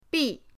bi4.mp3